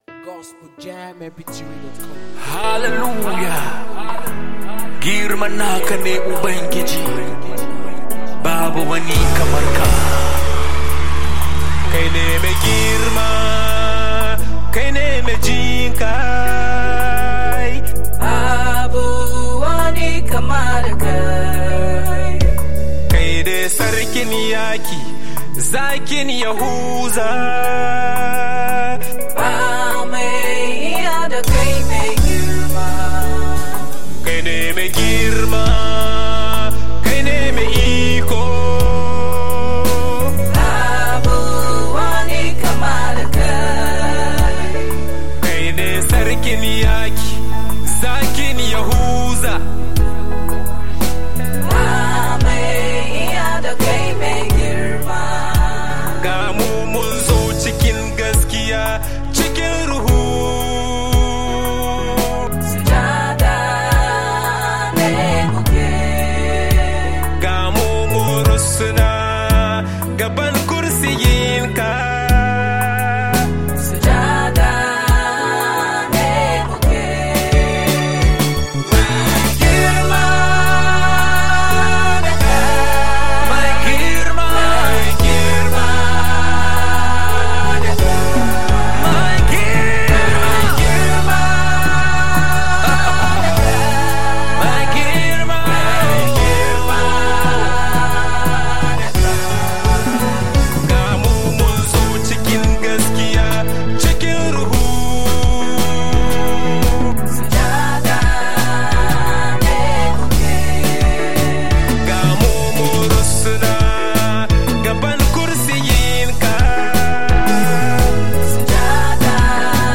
a powerful worship song